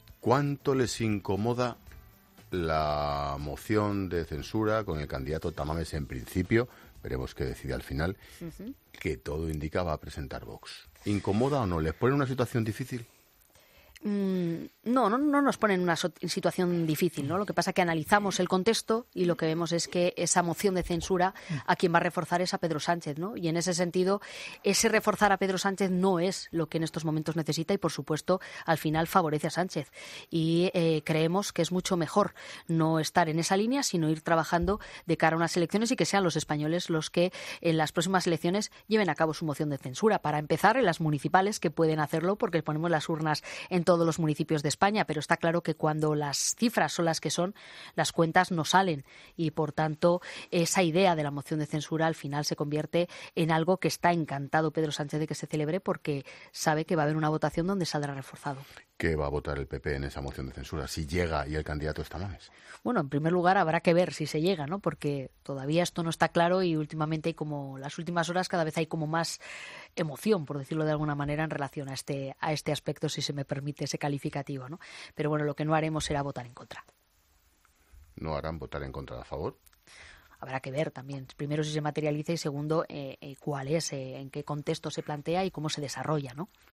La portavoz de los populares ha pasado por los micrófonos de La Linterna, donde ha respondido a Patxi López y la moción de censura de Tamames